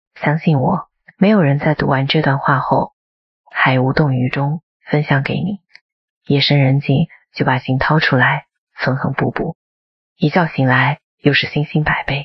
为放松内容打造的舒缓旁白之声
体验专为正念、睡前故事和情感叙事而设计的沉静、洪亮的 AI 声音。
文本转语音
温和音调
放松人声
冥想引导